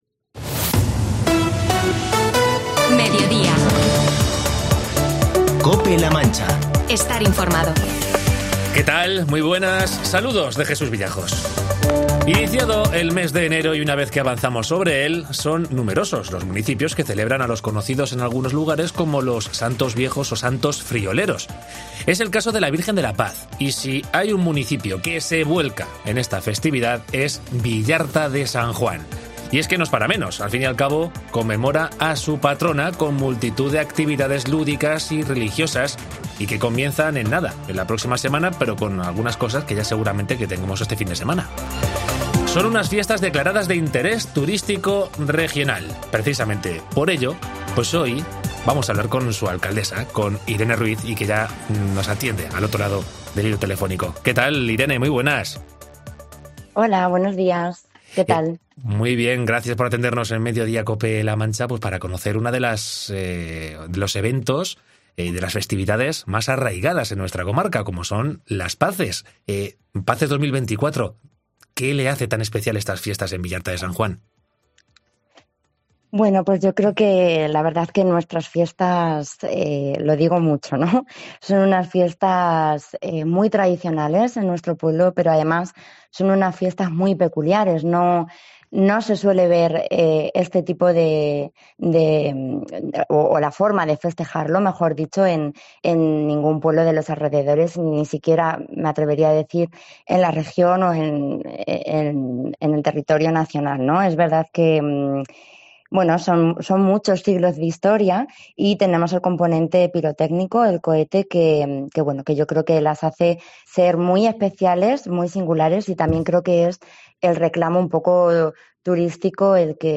Entrevista a Irene Ruíz, alcaldesa de Villarta de San Juan
La próxima semana, Villarta de San Juan celebrará con multitud de actividades lúdicas y religiosas la fiesta de "Las Paces 2024" en honor a su patrona, la Santísima Virgen de la Paz. En Mediodía Cope, hemos charlado con su alcaldesa, Irene Ruíz, quien ha invitado a toda la comarca de La Mancha de disfrutar de unas fiestas únicas en toda Castilla-La Mancha.